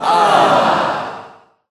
audienceSad.ogg